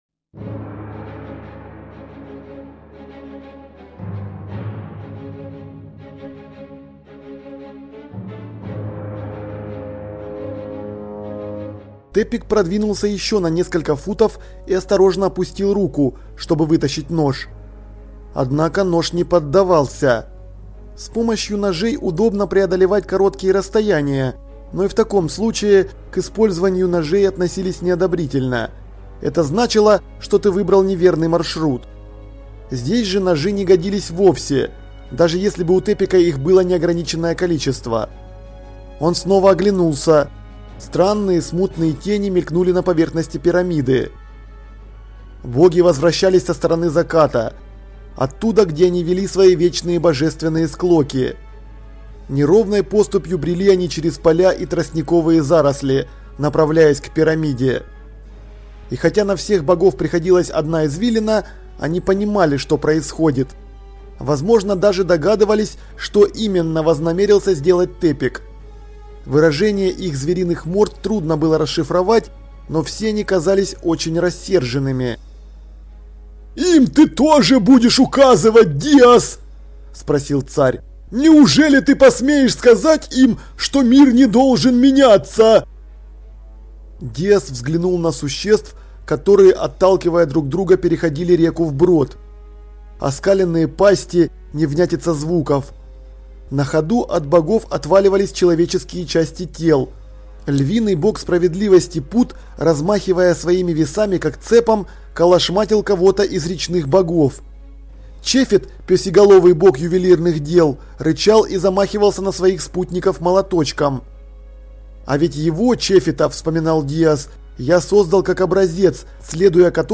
Аудиокнига Пирамиды
Качество озвучивания весьма высокое.